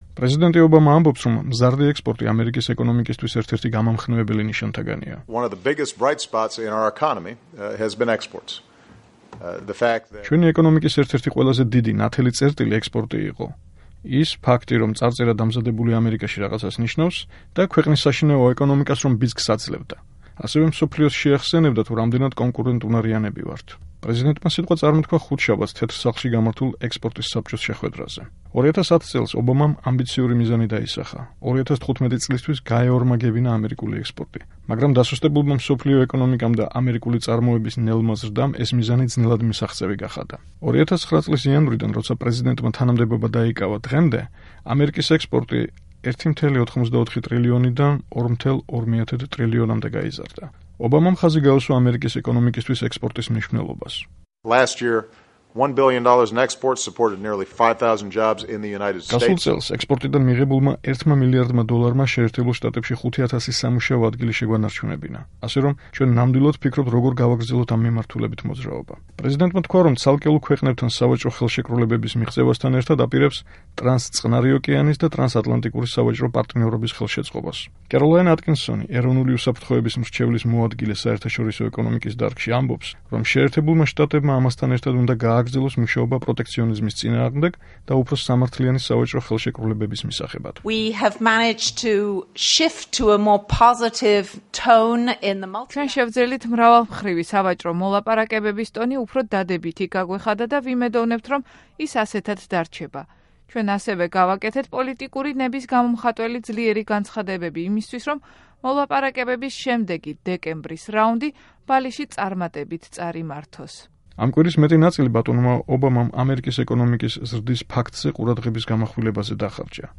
პრეზიდენტი სიტყვით ხუთშაბათს თეთრ სახლში გამართულ ექსპორტის საბჭოს შეხვედრაზე გამოვიდა